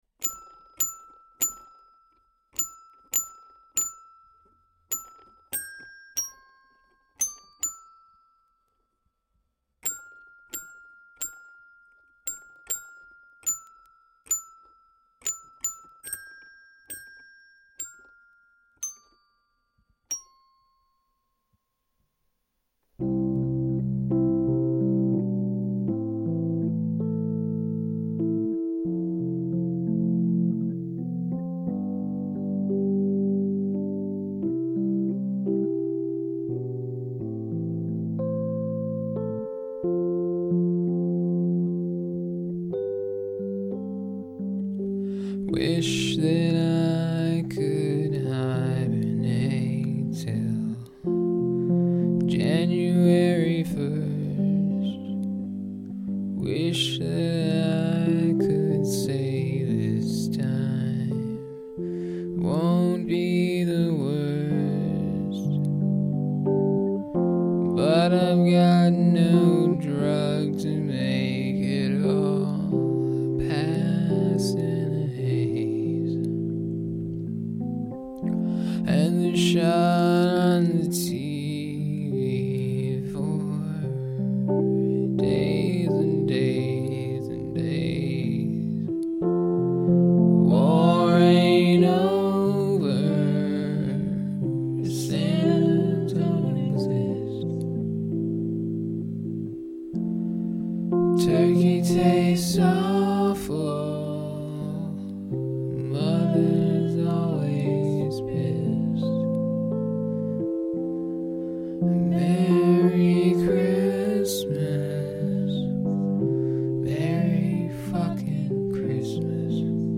his quaalude and rhodes inspired cover.